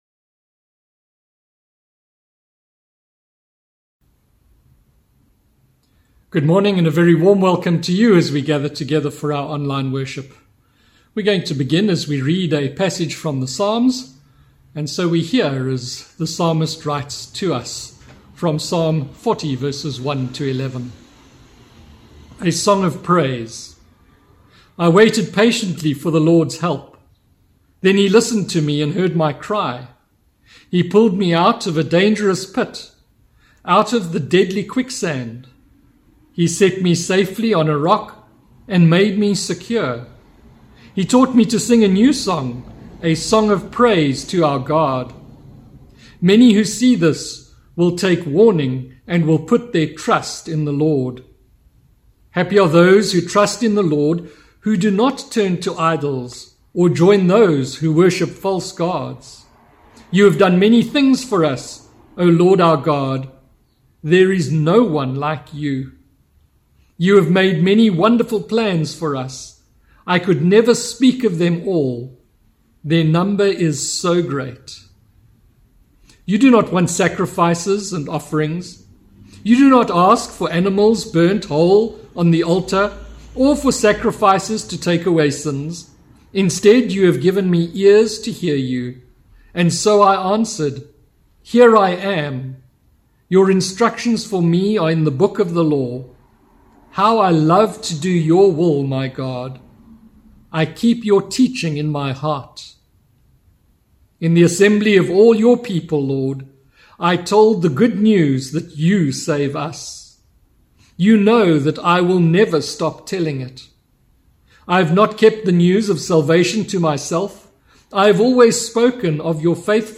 Sermon – 11th August